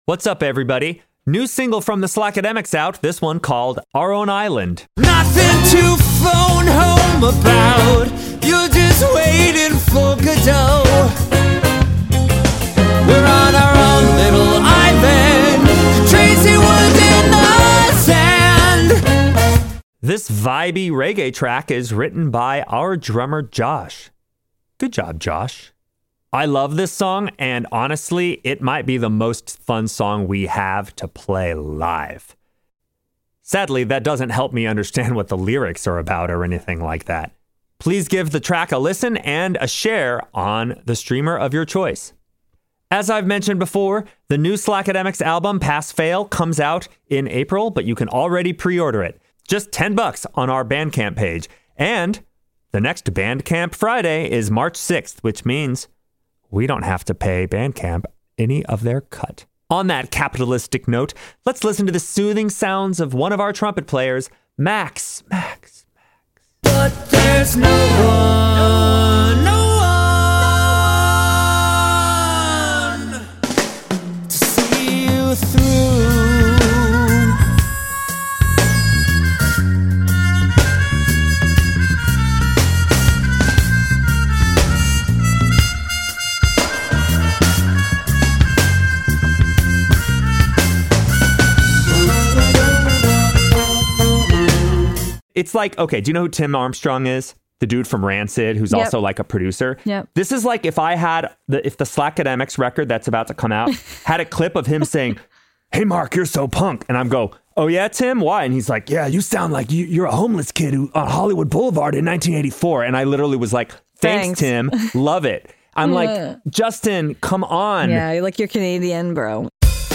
In this week’s Sidetrack, we come back once again to discuss the eight nominees for “Album of the Year” at the 2026 Grammys. Tune in to hear our hosts deep-dive on the high and low points of this year’s crop of nominees. As a bonus: our thoughts on the “Song of the Year” and “Record of the Year” categories!